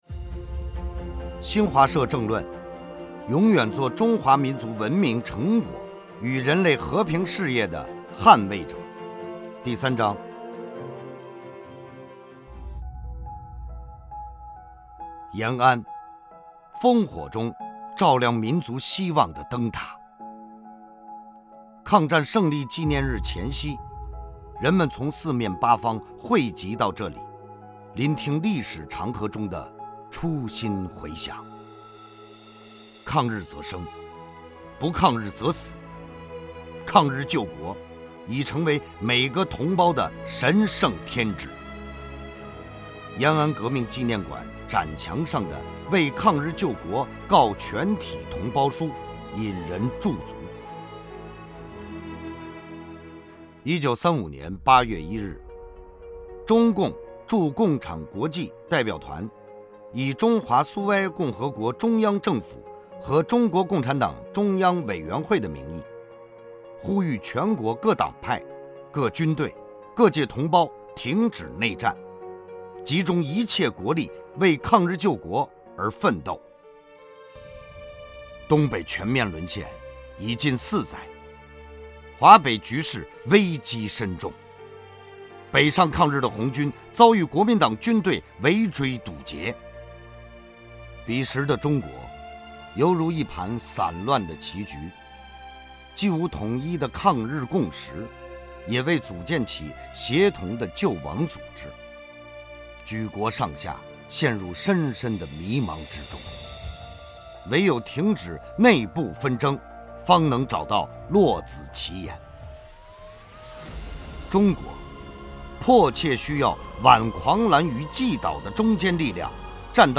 （有声剧版）
我们邀请诵读嘉宾吴樾演播“第三章”。
新华社政论：第三章（诵读版）